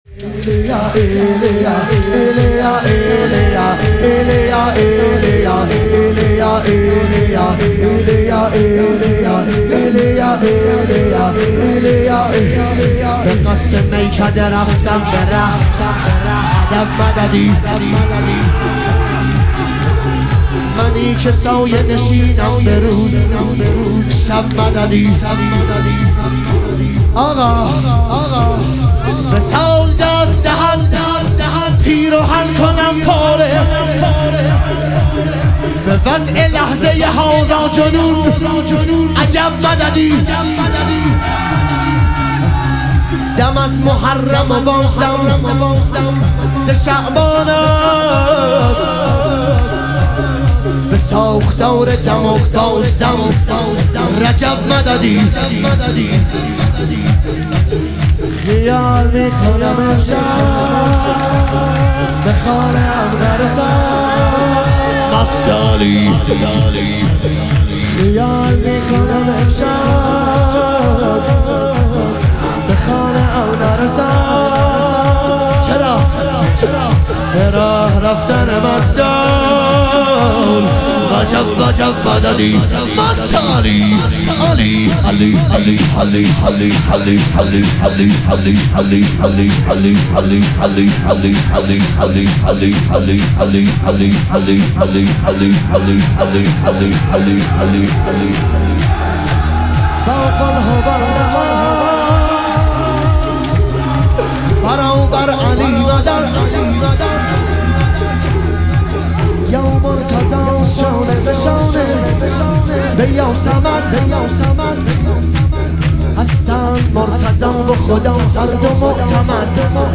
شهادت امام صادق علیه السلام - شور